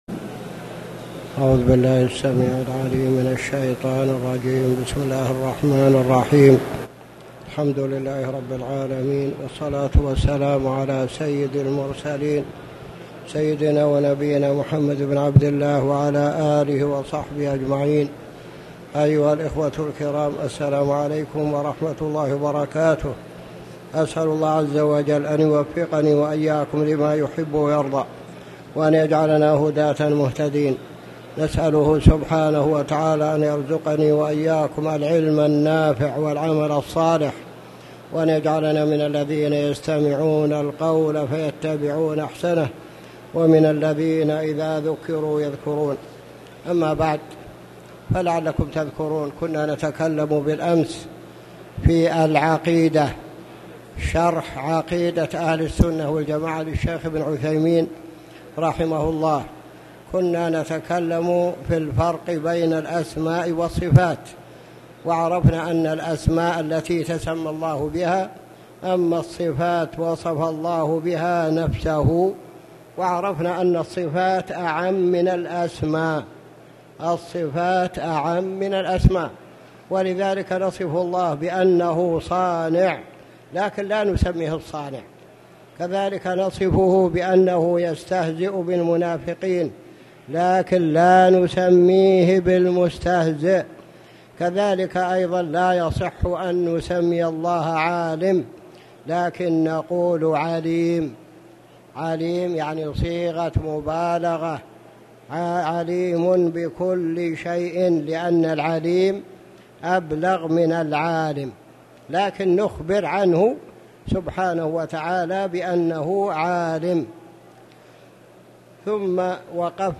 تاريخ النشر ١٢ شعبان ١٤٣٨ هـ المكان: المسجد الحرام الشيخ